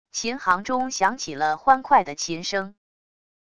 琴行中响起了欢快的琴声wav音频